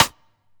Perc [ Gang ].wav